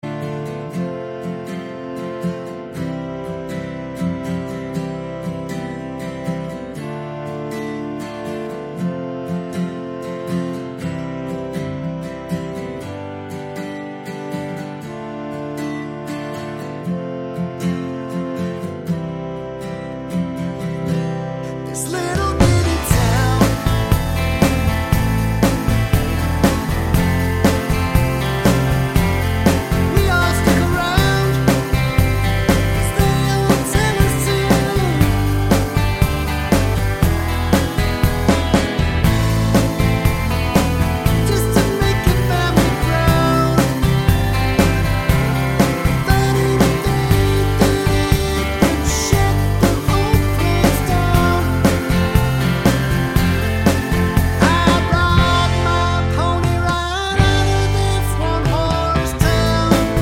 no Backing Vocals Country (Male) 4:15 Buy £1.50